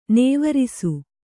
♪ nēvarisu